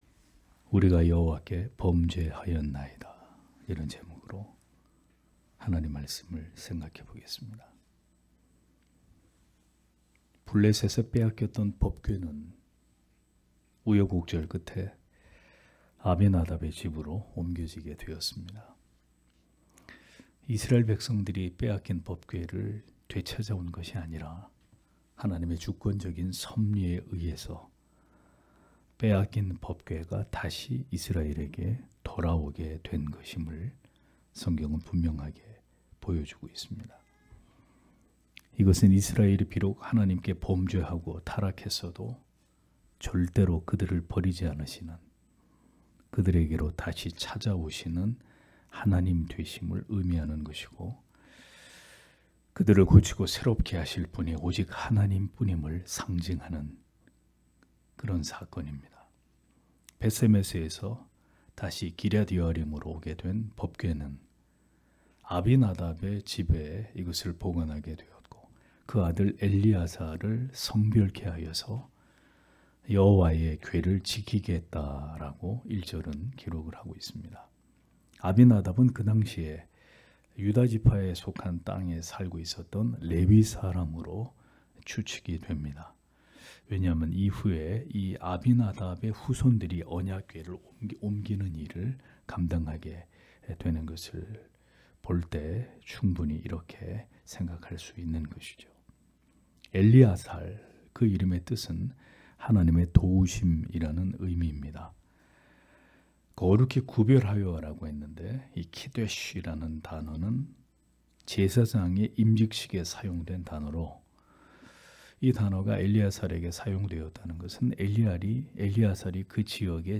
금요기도회 - [성찬 사경회2] 우리가 여호와께 범죄하였나이다 (삼상 7장 1- 12절)